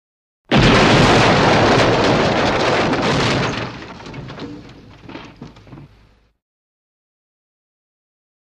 Building Demolition 1; Dynamite Explosion / Whoosh / Falling Debris, Medium Perspective.